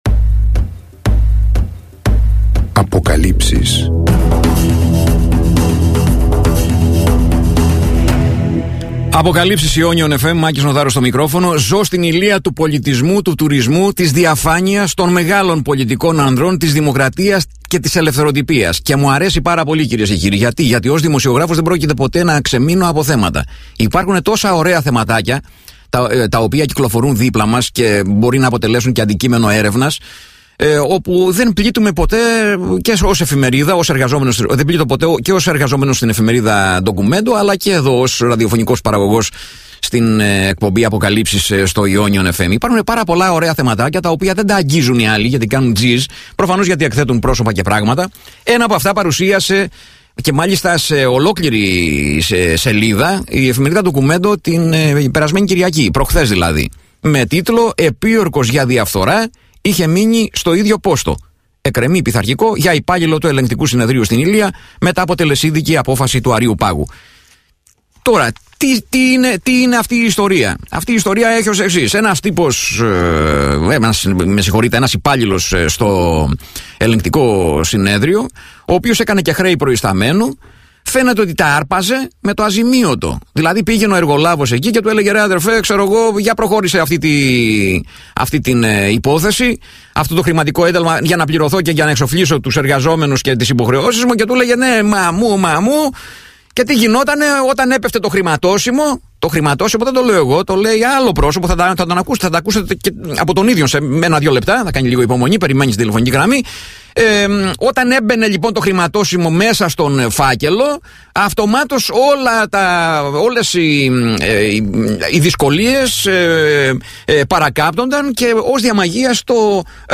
συνέντευξή